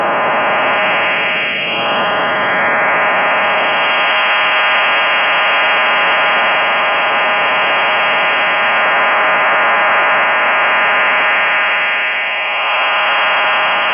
Modulation: 12 carriers DBPSK or DQPSK + pilot tone
Bandwidth: 2.7 kHz
Baud rate: 12 x 120 Bd